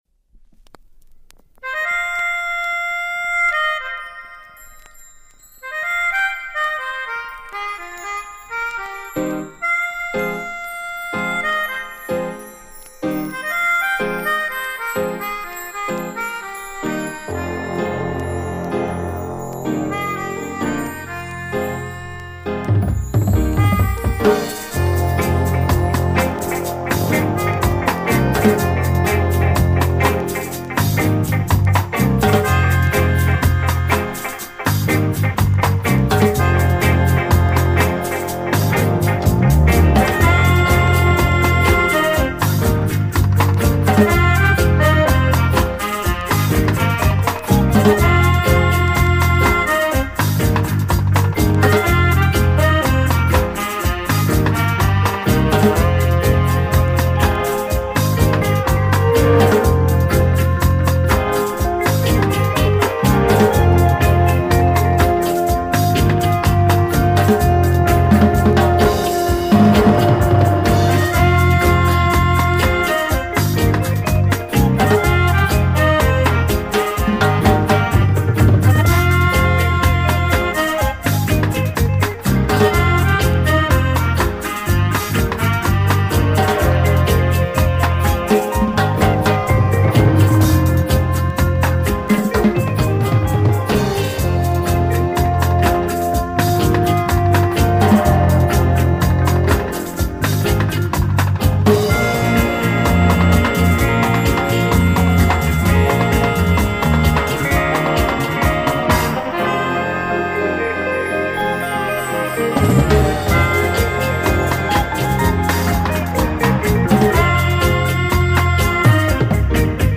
This week new repress crucial selection.
Listen , enjoy and share positive reggae vibes